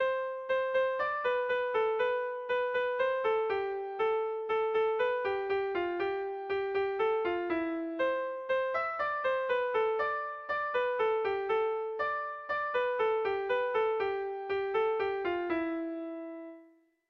Irrizkoa
Zortziko txikia (hg) / Lau puntuko txikia (ip)